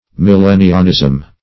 Search Result for " millennianism" : The Collaborative International Dictionary of English v.0.48: Millennianism \Mil*len"ni*an*ism\, Millenniarism \Mil*len"ni*a*rism\, n. Belief in, or expectation of, the millennium[2]; millenarianism.